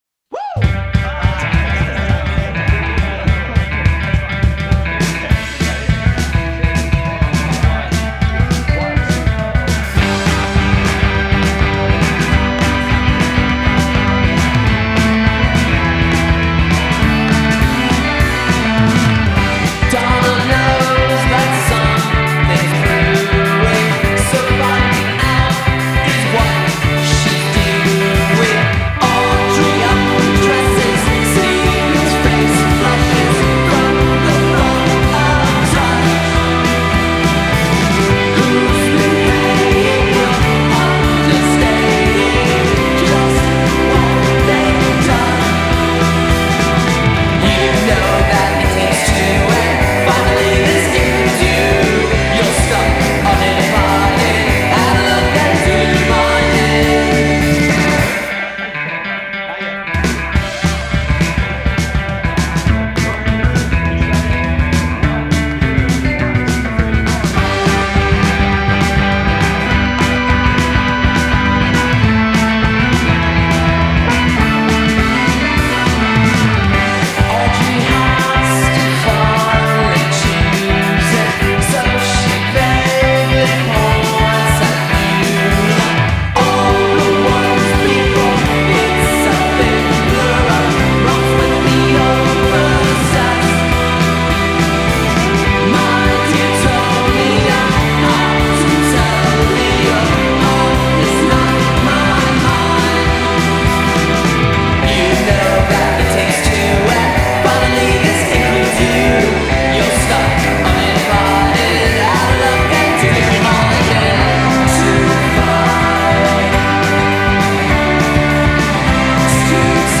e questo rende il loro agguerrito indiepop